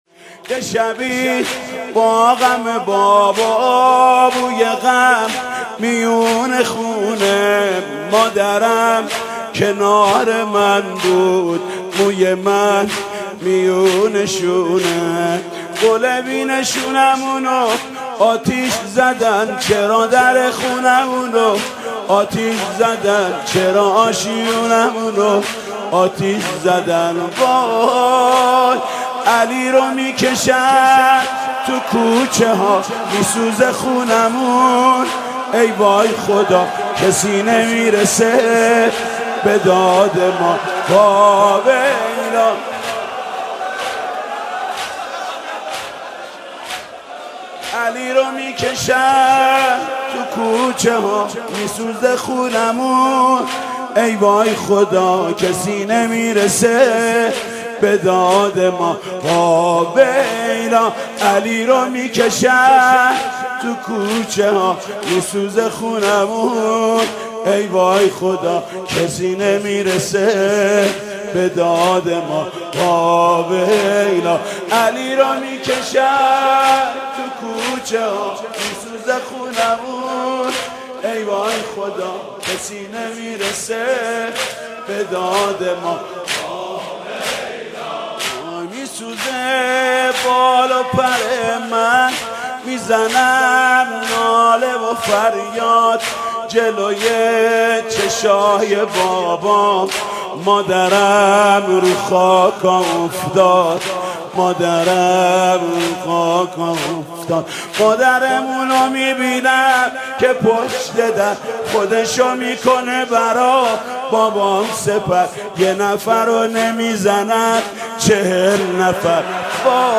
زمینه؛ علی رو می کشند تو کوچه ها ...
🎧 بشنویم | به مناسبت ایام فاطمیه